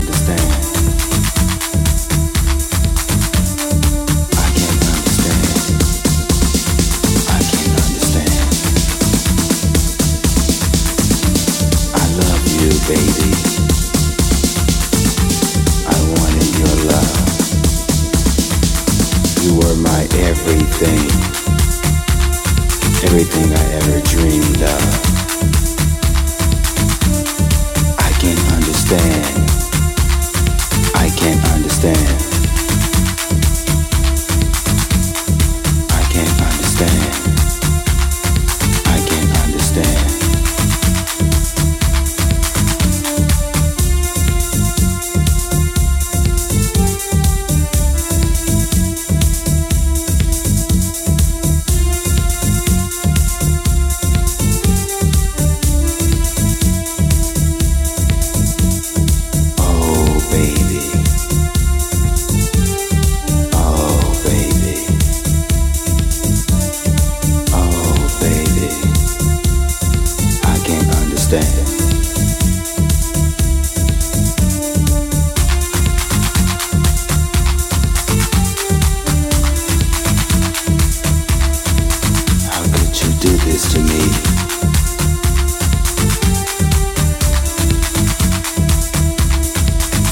Chicago house classic